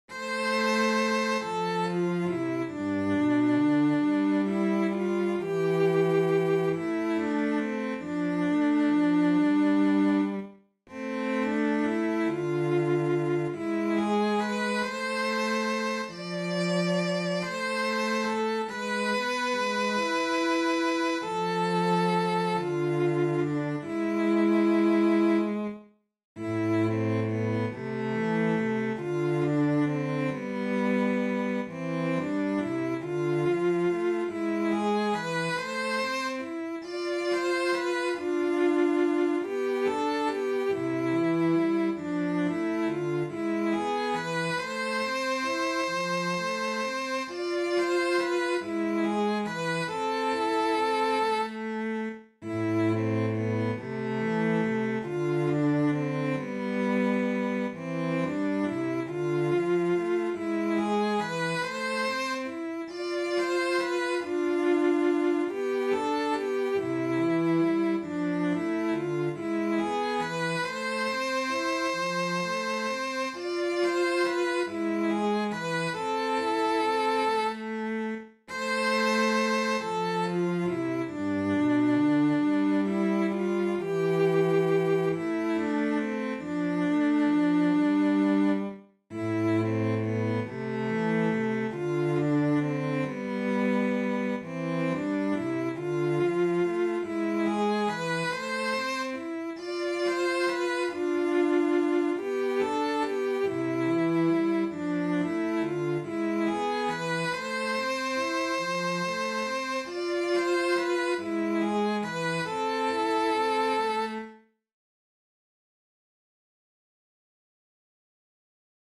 Sateesta-pisara-unelma-kasvusta-sellot.mp3